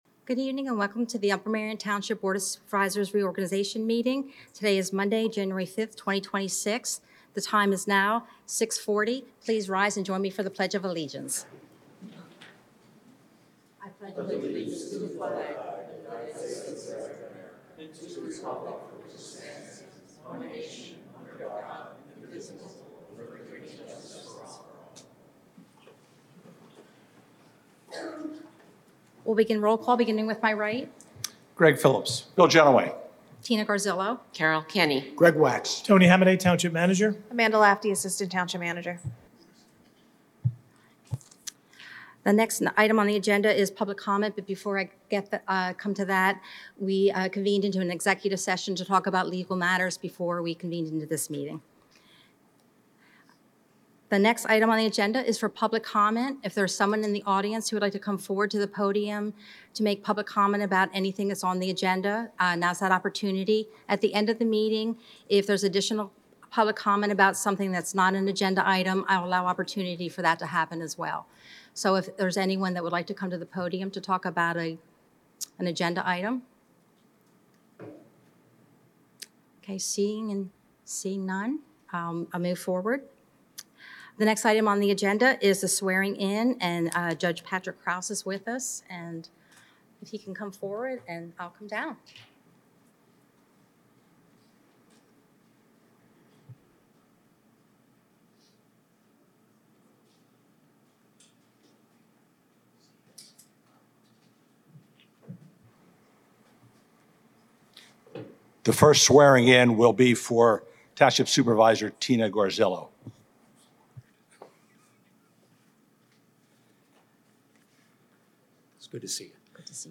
BOS Reorg Meeting - January 5, 2026